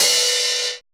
RX OHH.wav